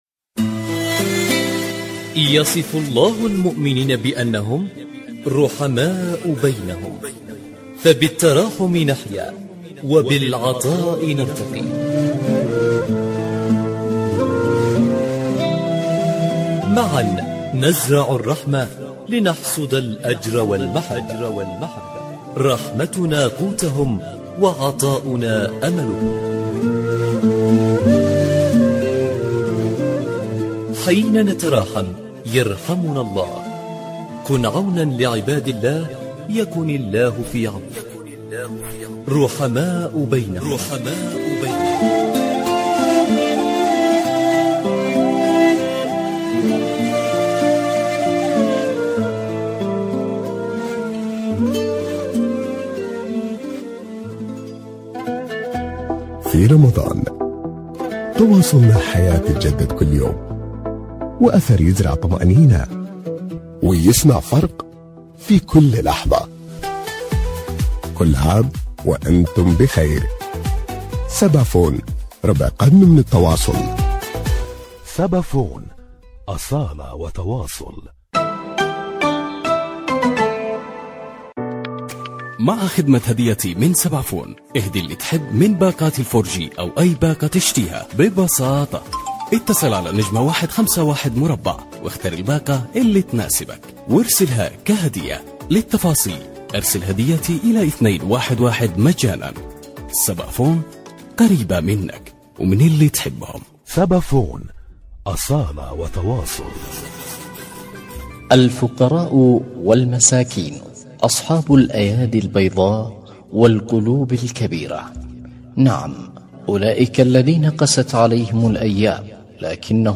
رحماء بينهم، برنامج إذاعي يأخذكم في رحلة إذاعية قصيرة ، نستكشف خلالها العديد من الحالات الإنسانية التي تحتاج الى مد يد العون في شهر الخيرات، ويسلط الضوء على حالات إنسانية.